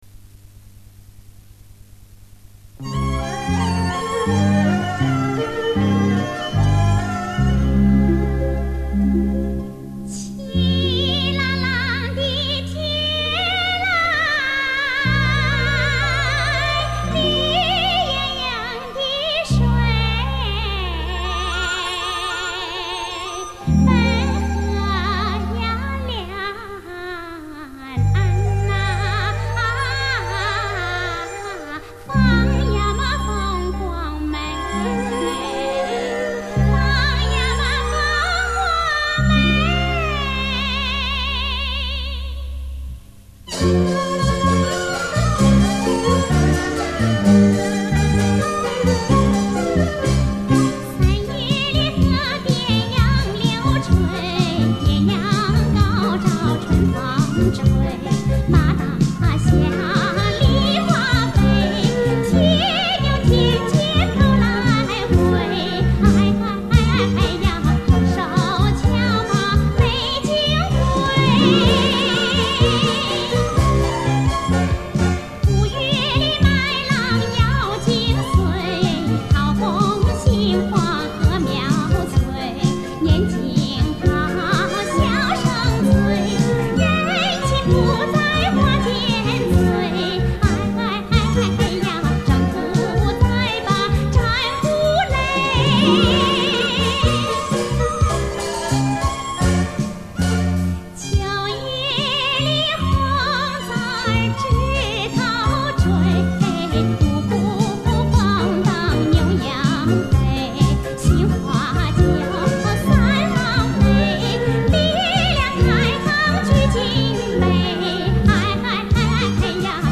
也是很浓的山西腔